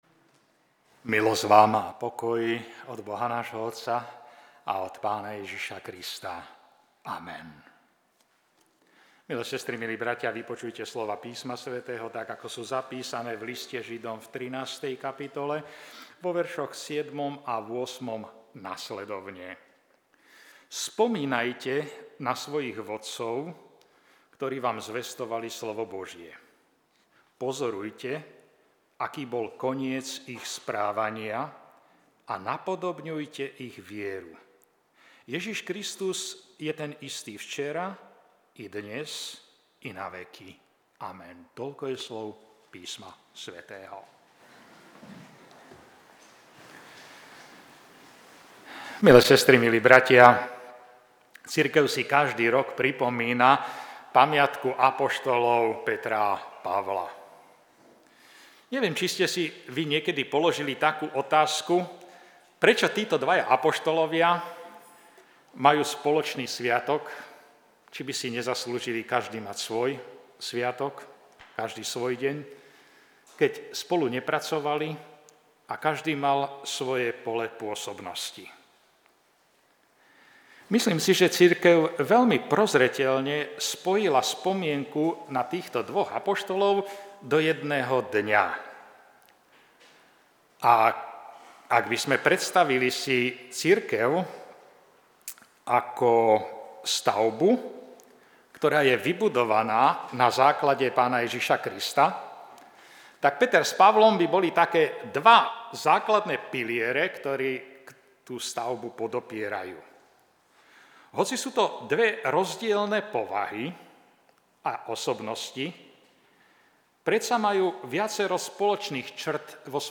Ľudia, na ktorých sa nezabúda (Žd 13, 7-8) káže